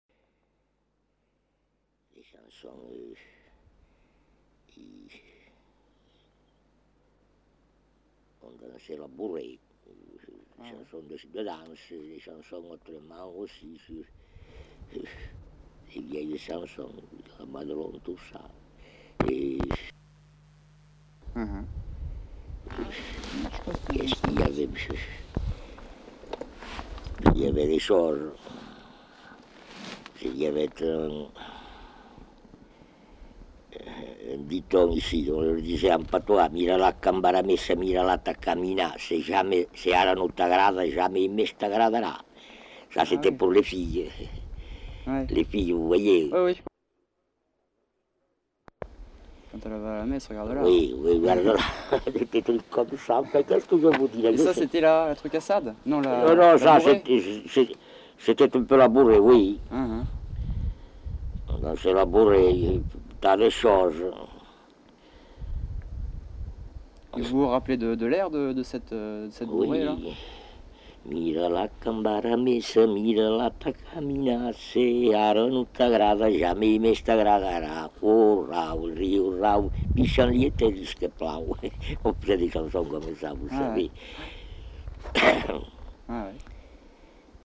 Aire culturelle : Couserans
Lieu : Cominac (lieu-dit)
Genre : chant
Effectif : 1
Type de voix : voix d'homme
Production du son : chanté
Danse : bourrée